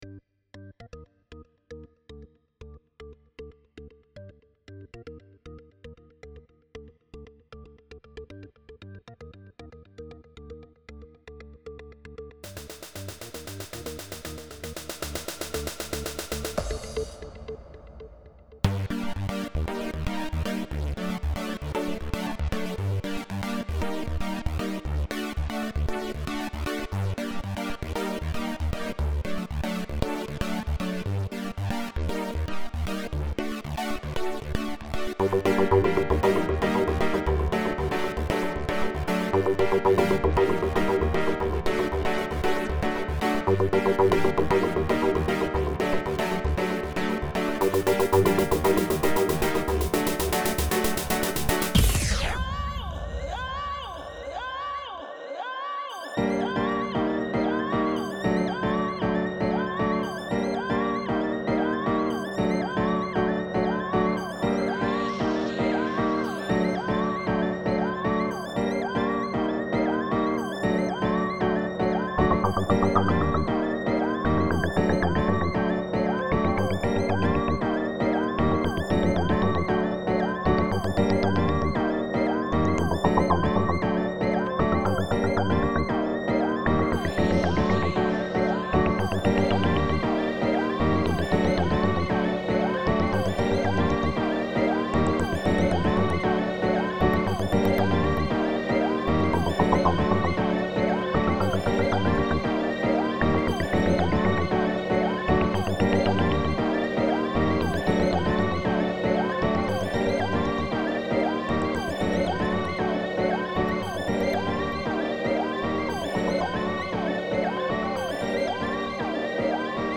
L'electro n'est pas du tout mon style de prédilection donc j'aimerais avoir l'avis de gens avertis sur la "valeur artistique" de ce morceau mais plus particulièrement sur son mixage.
P.S. : Il n'est pas masterisé donc montez le volume !